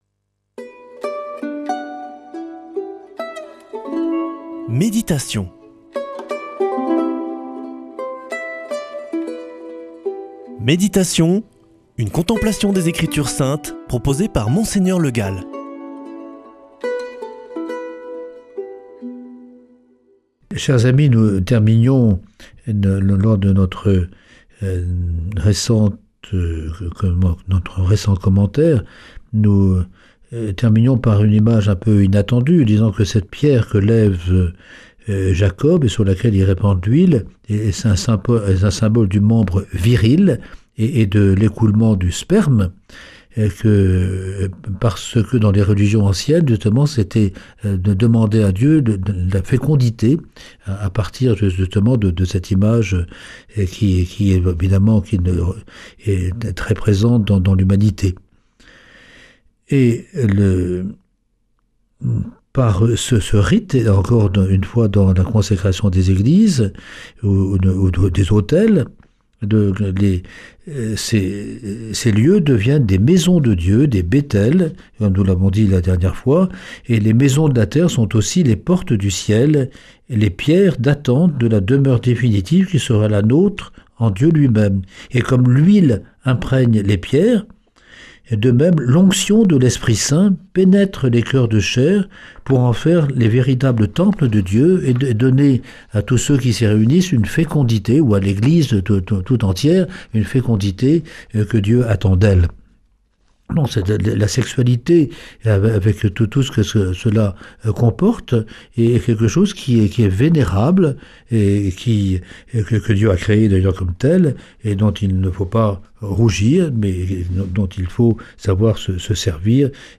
Méditation avec Mgr Le Gall